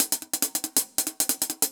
Index of /musicradar/ultimate-hihat-samples/140bpm
UHH_AcoustiHatC_140-04.wav